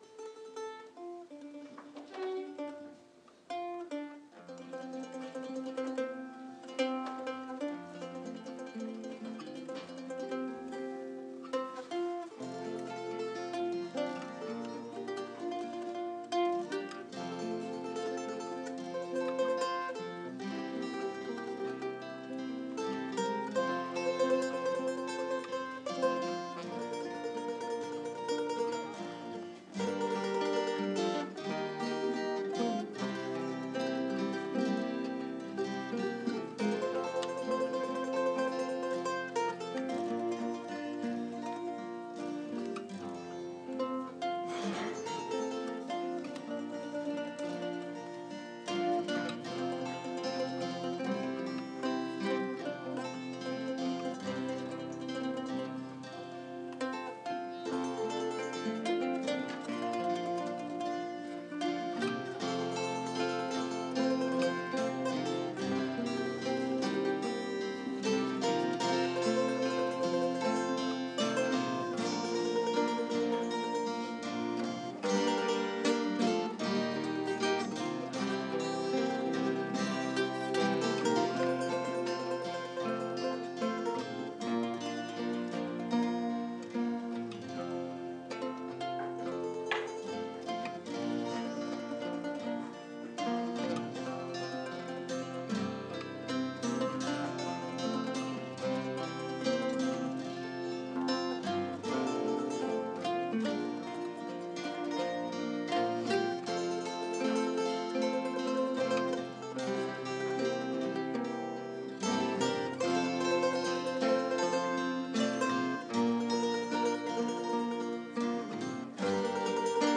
Instrumental
Couple of errors and I would like an extra bar between verses, but not bad for a first go.